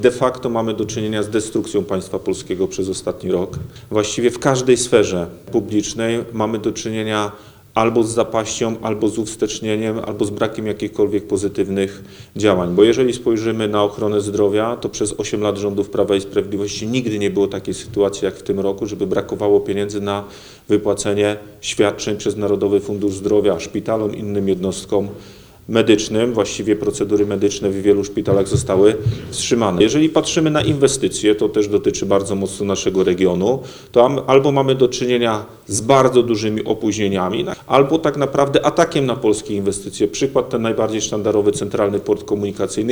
– To data trudna i smutna – podkreślił Zbigniew Bogucki, poseł Prawa i Sprawiedliwości, podczas konferencji prasowej.